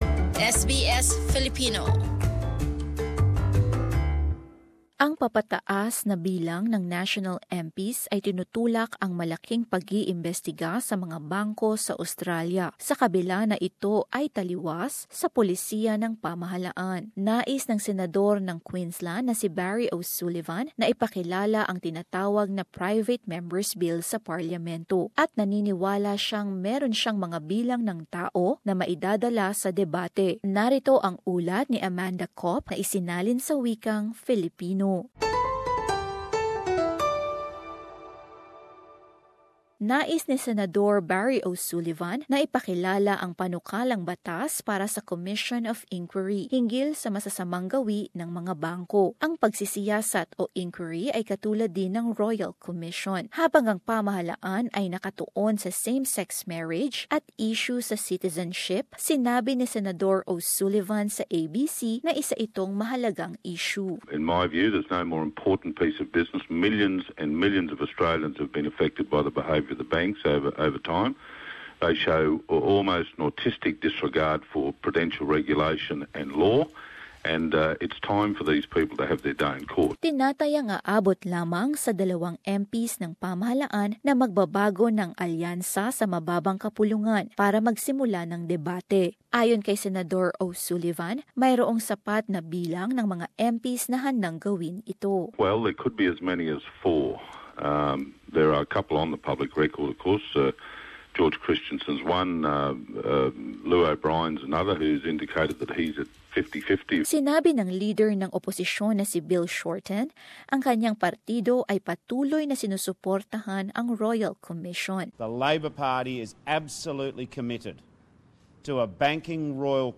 Narito ang ulat